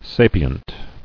[sa·pi·ent]